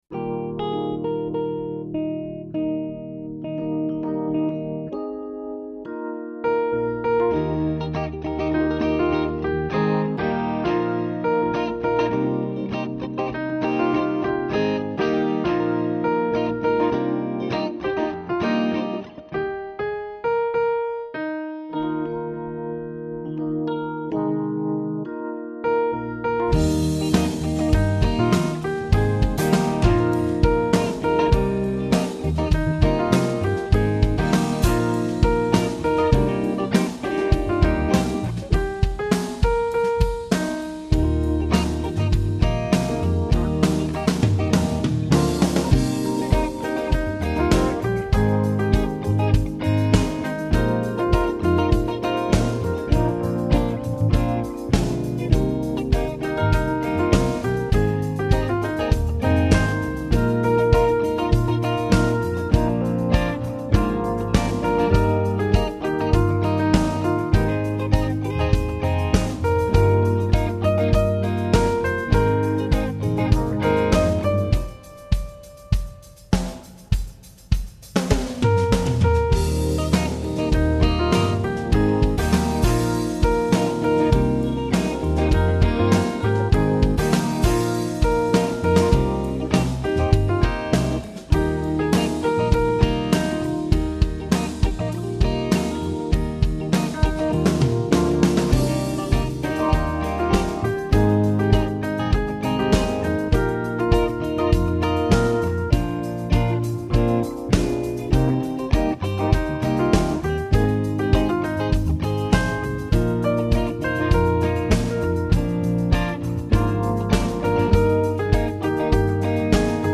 song of praise